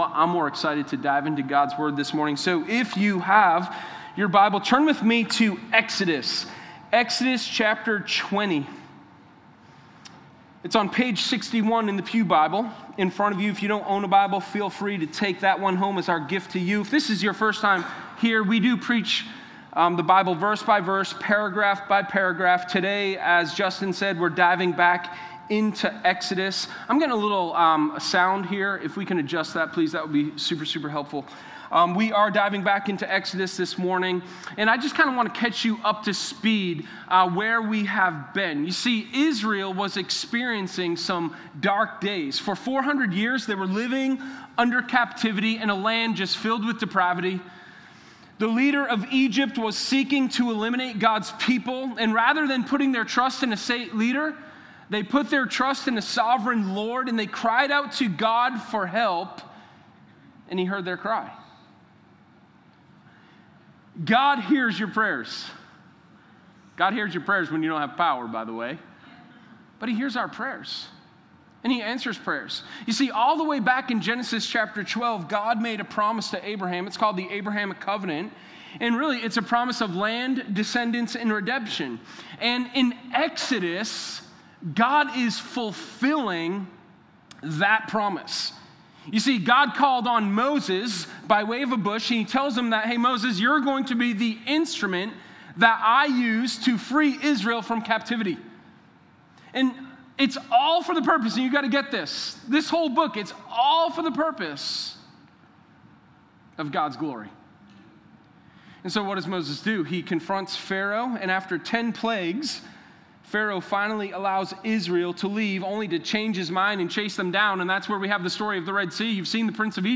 Sermon1014_TheLawMatters.mp3